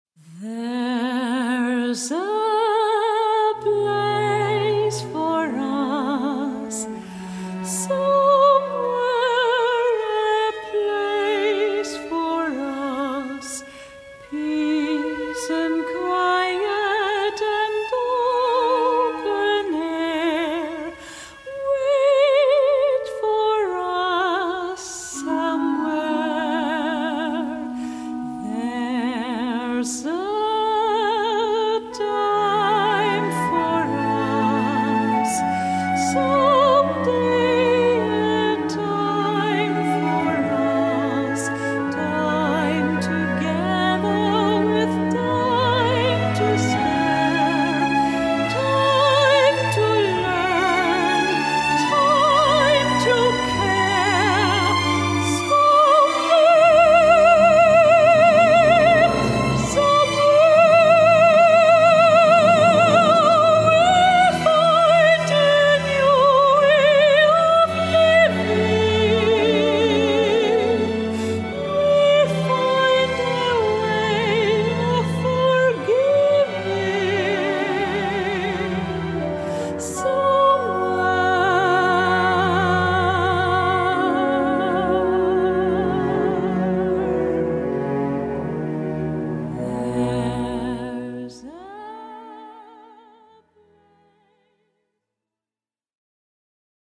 Hochzeitssängerin Hannover/Niedersachsen